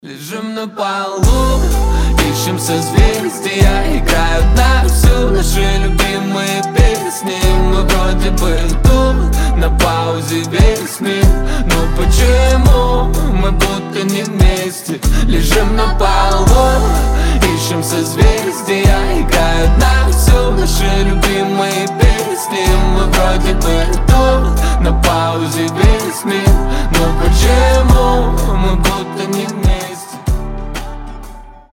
• Качество: 320, Stereo
красивые
лирика
дуэт